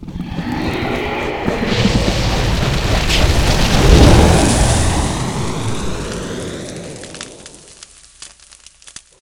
firebolt.ogg